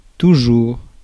Ääntäminen
US : IPA : /fəɹˈɛvɚ/